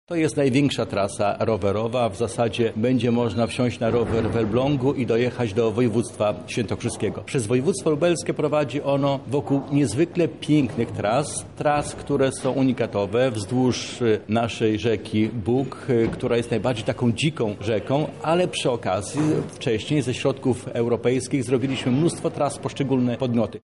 – To wspaniała opcja dla turystów – mówi Krzysztof Grabczuk, wicemarszałek Lublina